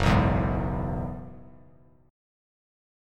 F#7sus2sus4 chord